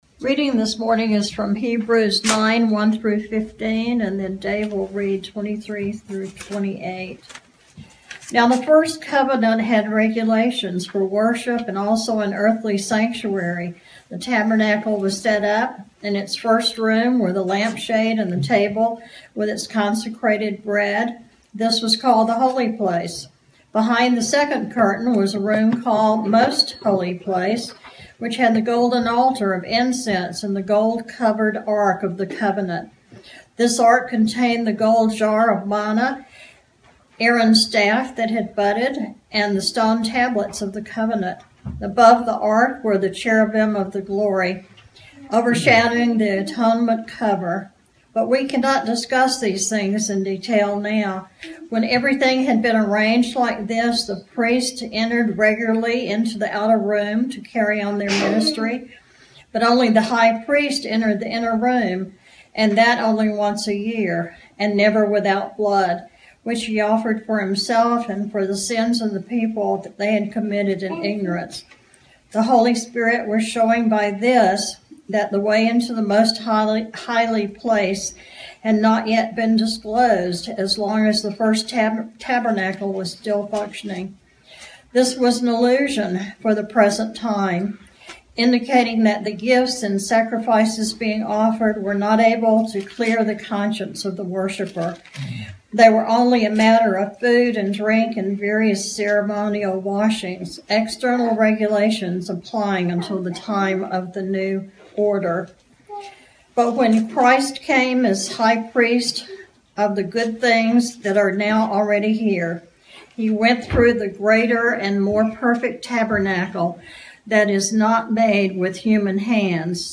Passage: Hebrews 9:1-12 Service Type: Sunday Morning